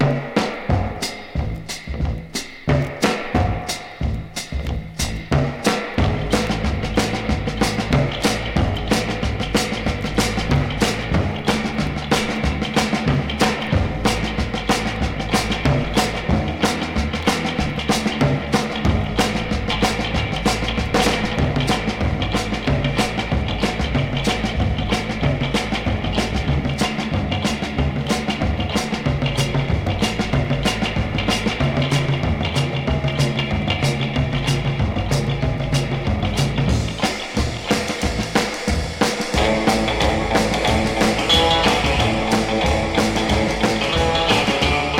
Rock'N'Roll, Jazz　USA　12inchレコード　33rpm　Mono